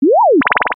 Звуки уведомлений для электронной почты
На этой странице собраны звуки уведомлений для электронной почты — короткие и четкие сигналы о новых письмах.